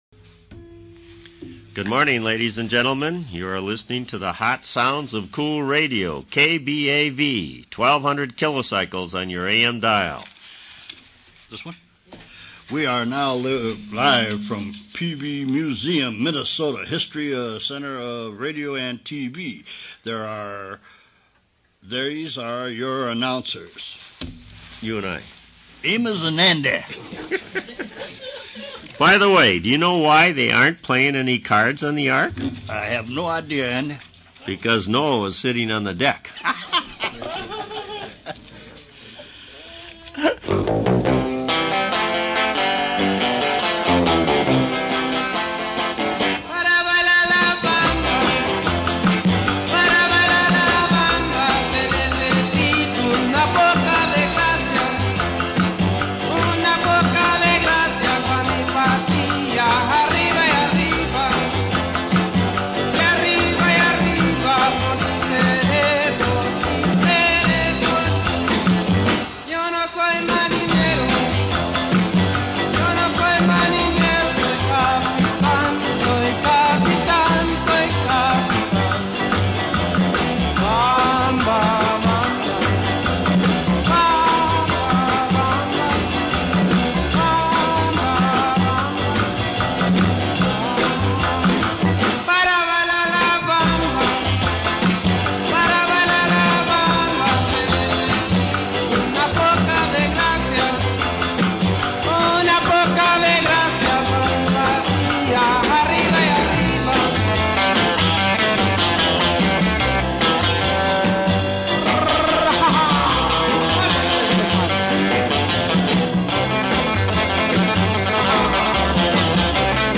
Ullr mock "radio broadcast" (MP3 file, 8.75 min, 4.1MB)